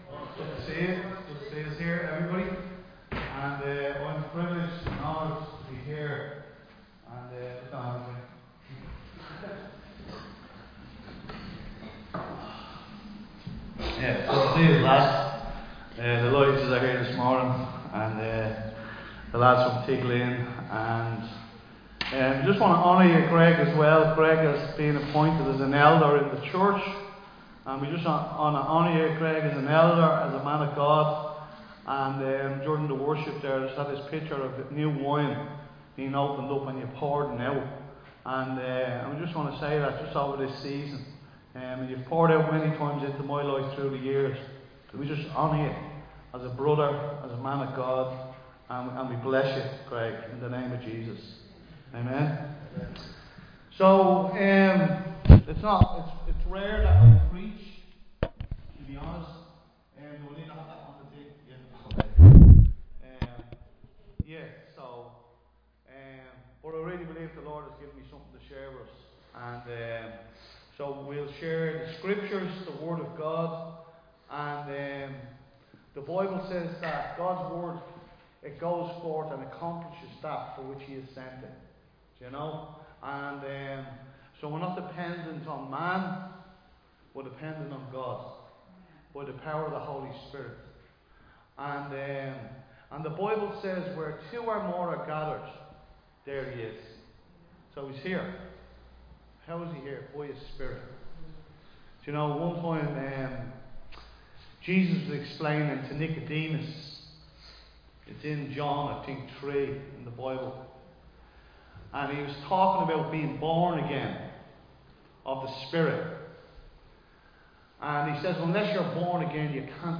Recorded live in Liberty Church on 11 May 2025